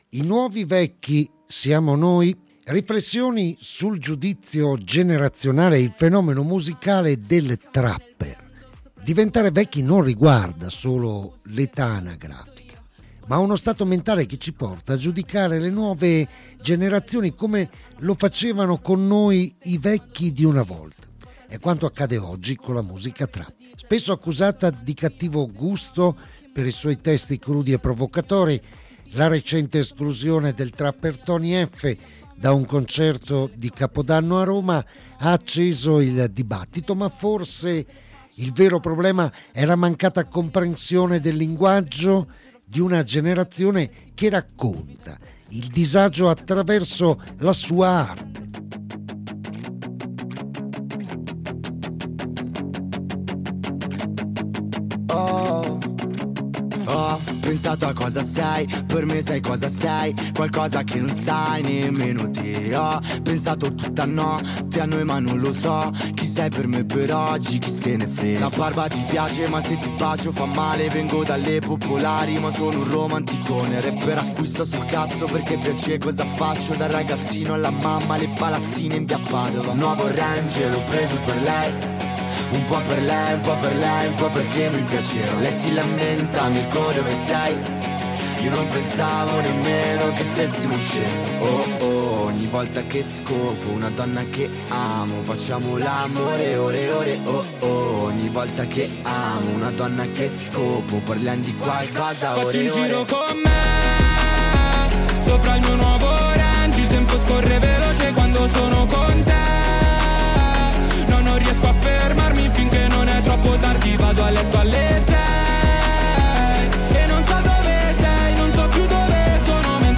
I-vecchi-siamo-noi-con-musica-completo.mp3